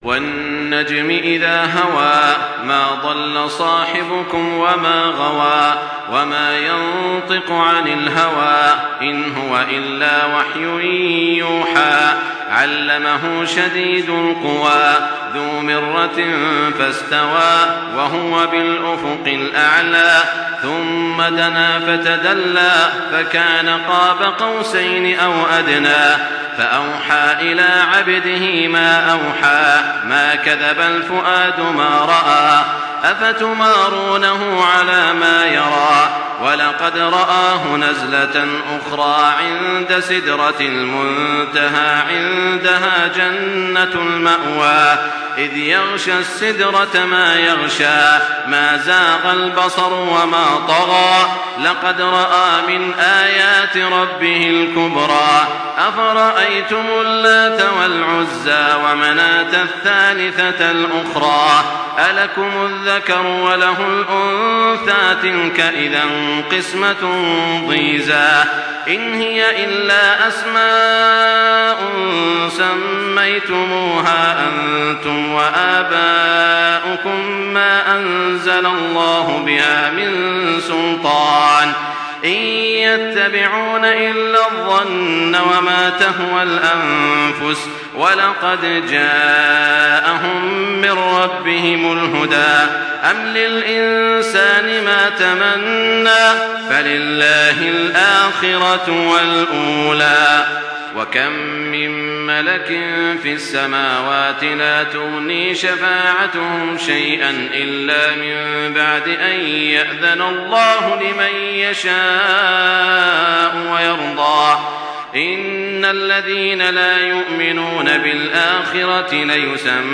تحميل سورة النجم بصوت تراويح الحرم المكي 1424
مرتل